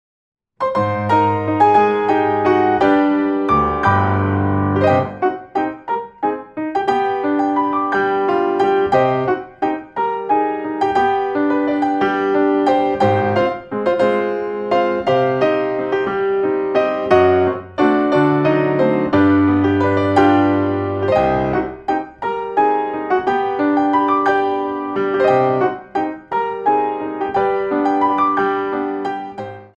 4 bar intro 3/4